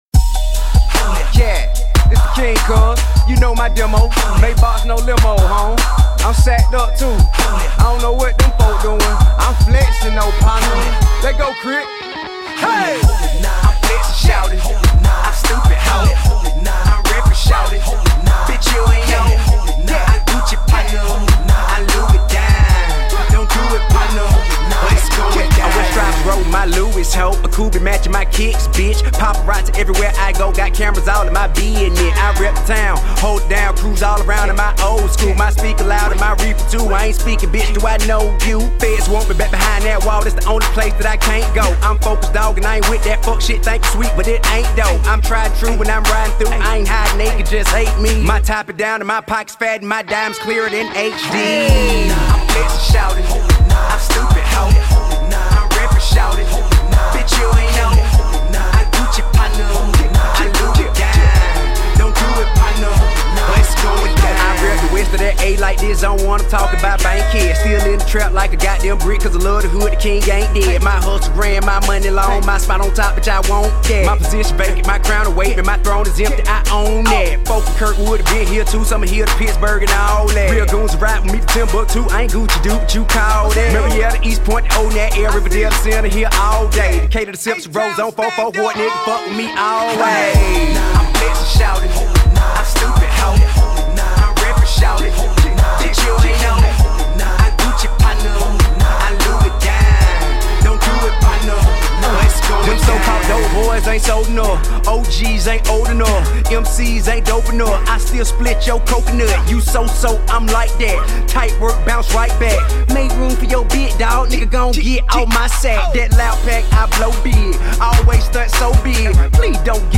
classic southern Hip-Hop